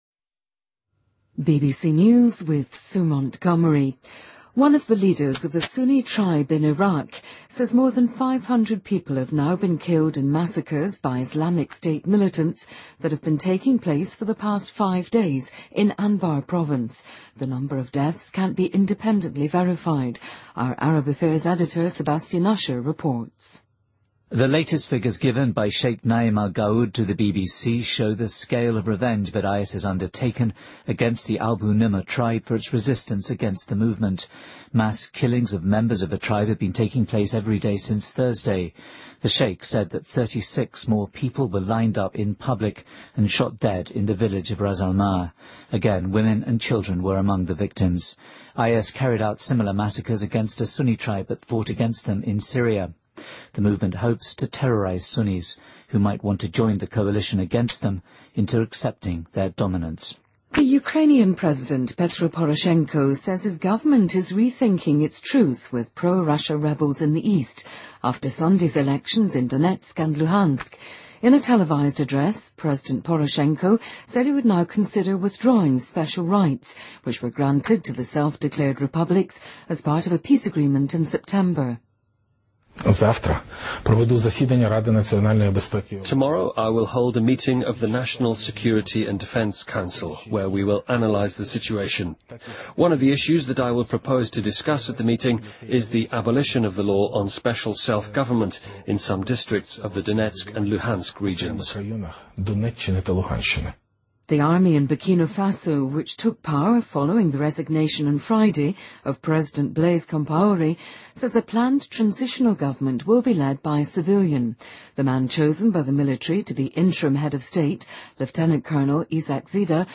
BBC news,最新BBC新闻